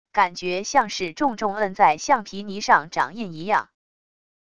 感觉像是重重摁在橡皮泥上掌印一样wav音频